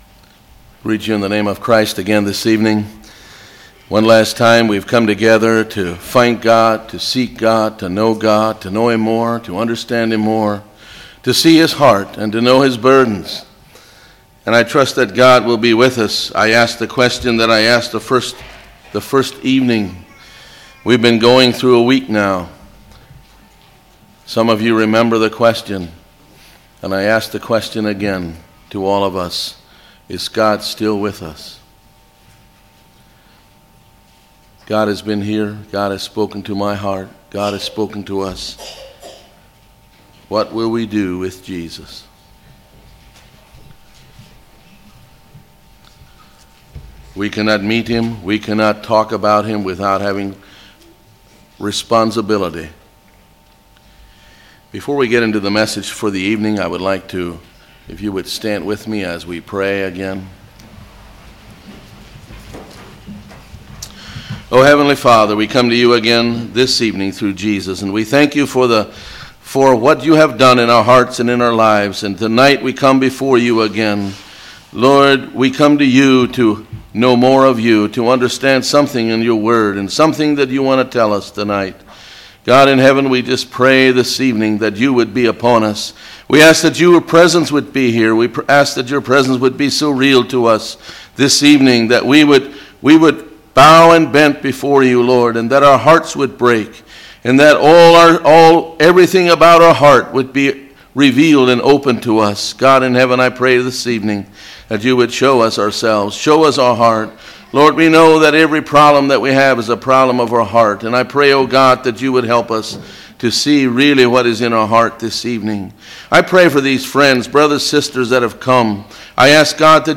Sermons
Congregation: Susquehanna Valley